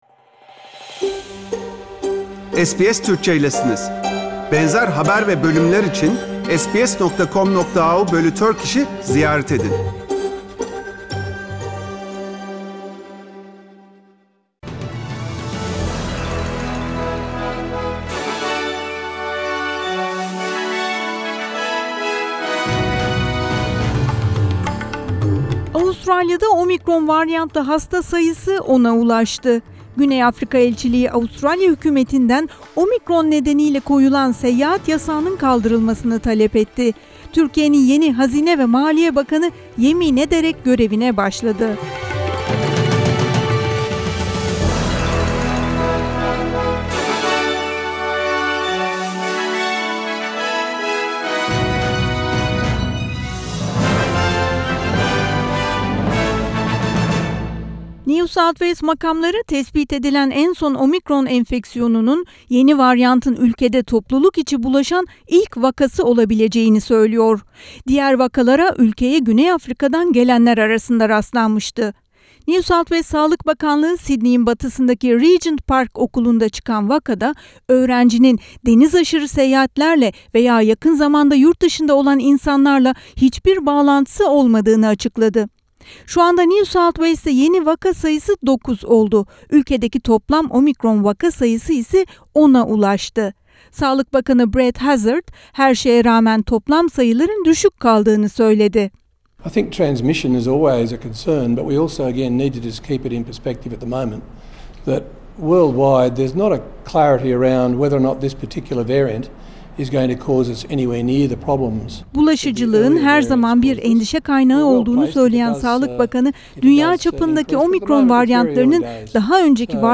SBS Türkçe Haberler 3 Aralık